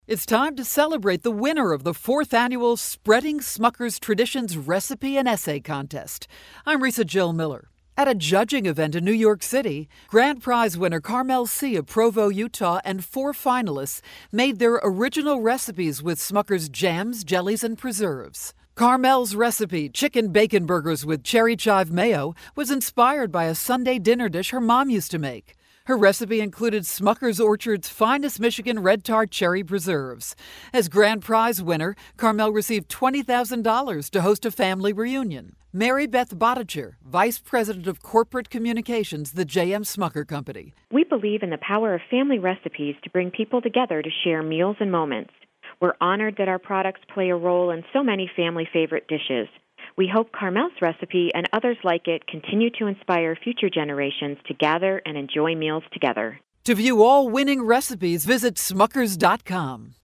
May 30, 2012Posted in: Audio News Release